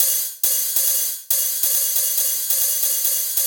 Brush Rides 138bpm.wav